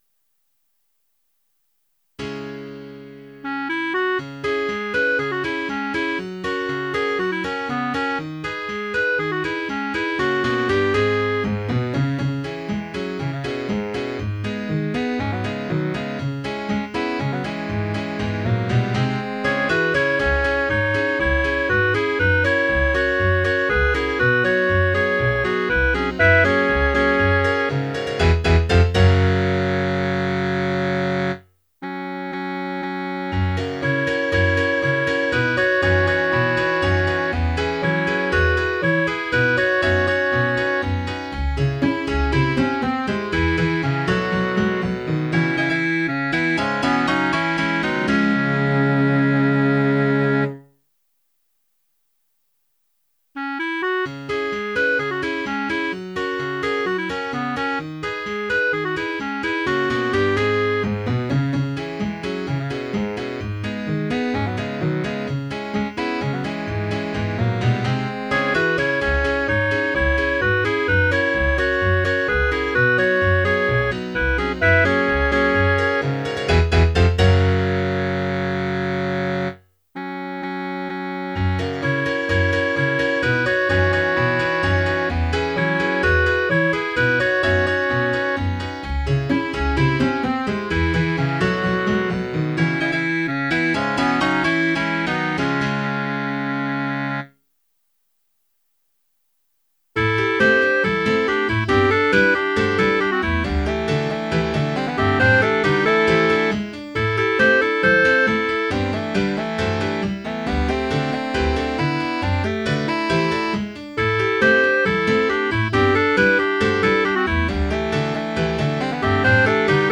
Title Can't Help Noticin' (SATB) Opus # 168 Year 0000 Duration 00:03:08 Self-Rating 4 Description A conversation between lovers about jealousy, about as serious as a Monty Python sketch. It sounds fine transposed up a half-step, which makes for easier pianoing. mp3 download wav download Files: wav mp3 Tags: Piano, Strings, Choral Plays: 1817 Likes: 0